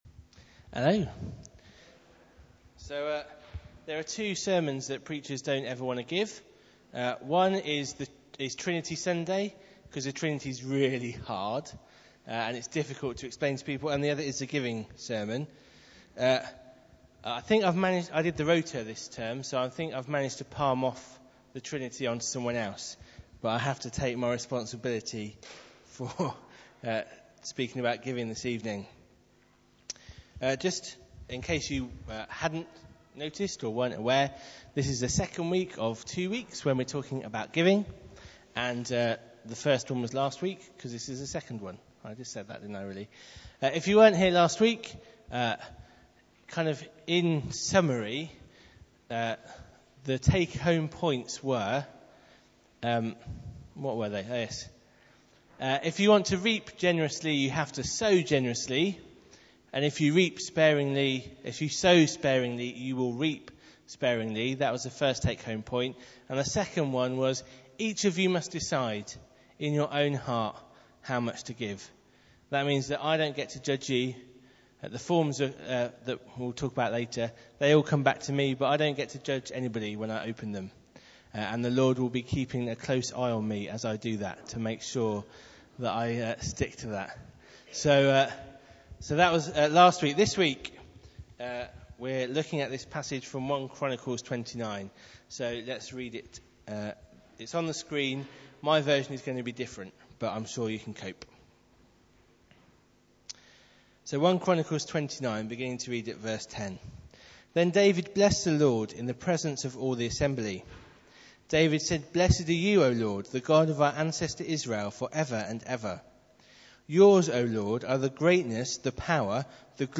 6pm evening service at St John’s